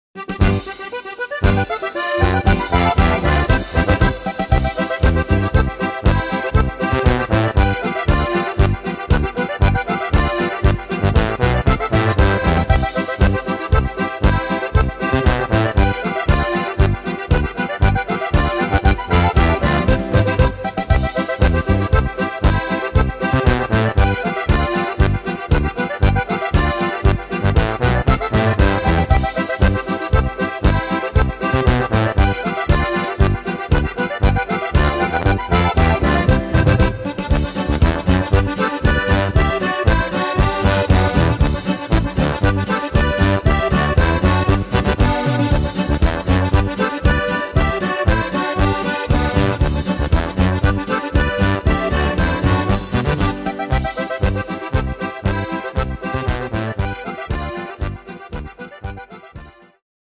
Tempo: 117 / Tonart: C / G / F-Dur